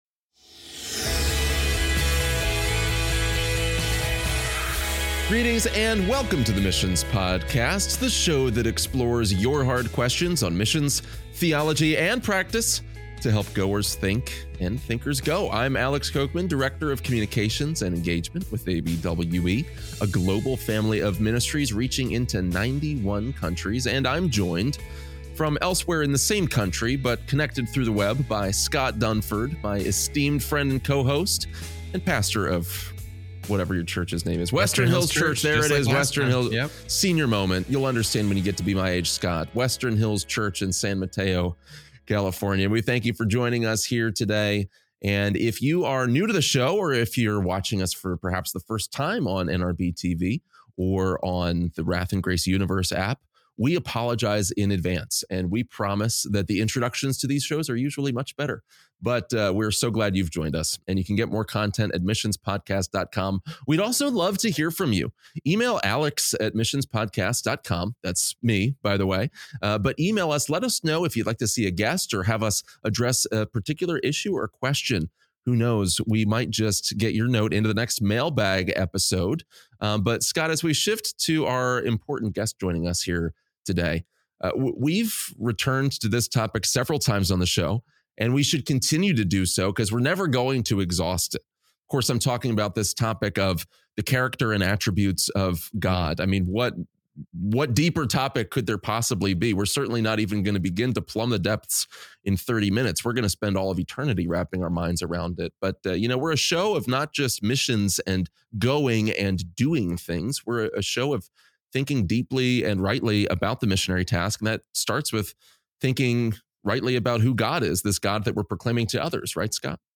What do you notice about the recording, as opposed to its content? The Missions Podcast goes on the road!